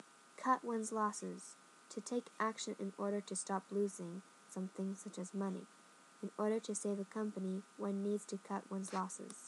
英語ネイティブによる発音は以下のリンクをクリックしてください。
CutOnesLosses.mp3